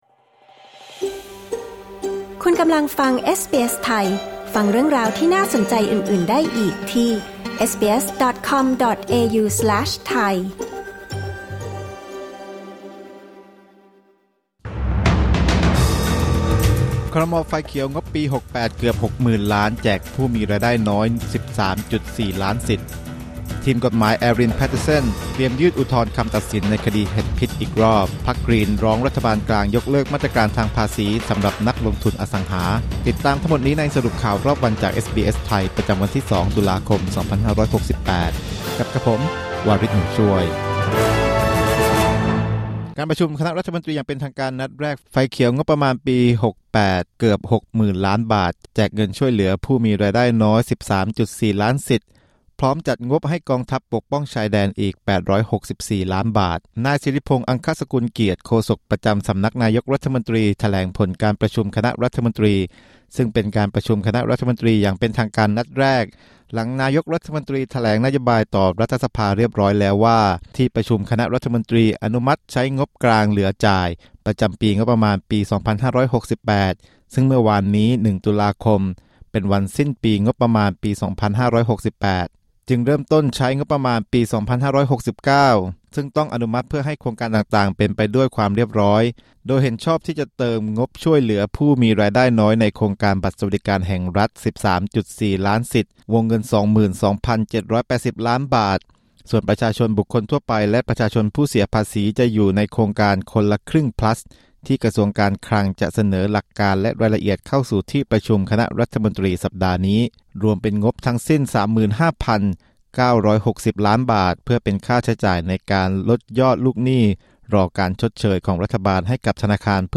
สรุปข่าวรอบวัน 2 ตุลาคม 2568